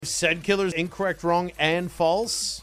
Play, download and share incorrect wrong and false original sound button!!!!
incorrect-wrong-and-false.mp3